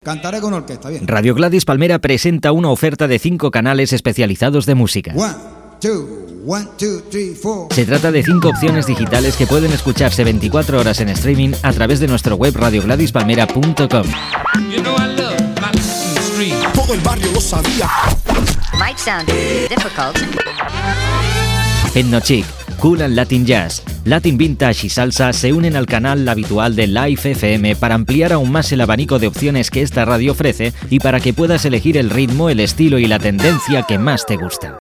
Anunci dels cinc canals musicals temàtics de la ràdio que es poden escoltar per Interet
Presentador/a